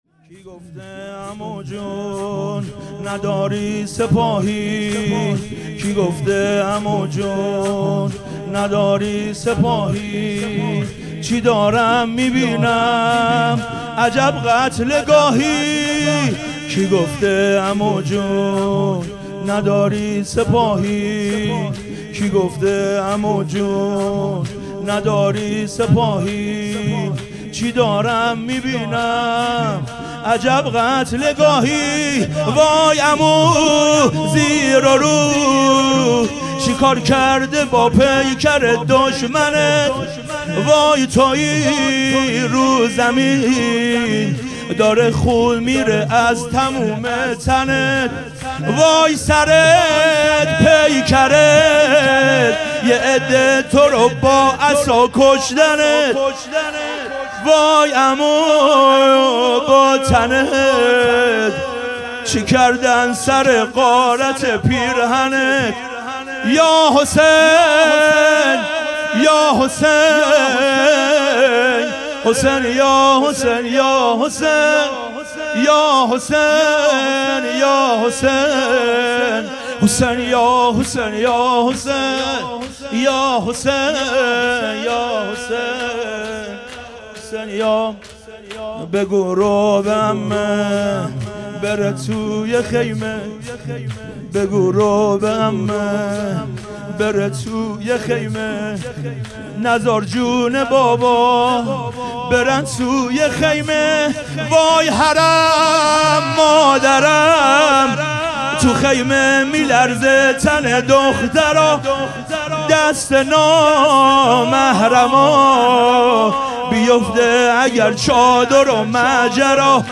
خیمه گاه - هیئت اصحاب الحسین(ع) - شب پنجم-شور-کی گفته عمو جون
هیئت اصحاب الحسین(ع)